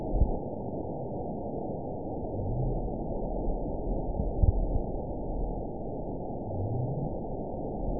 event 916118 date 12/25/22 time 10:29:29 GMT (2 years, 5 months ago) score 9.37 location INACTIVE detected by nrw target species NRW annotations +NRW Spectrogram: Frequency (kHz) vs. Time (s) audio not available .wav